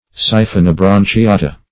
Search Result for " siphonobranchiata" : The Collaborative International Dictionary of English v.0.48: Siphonobranchiata \Si`pho*no*bran`chi*a"ta\, n. pl.
siphonobranchiata.mp3